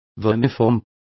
Complete with pronunciation of the translation of vermiform.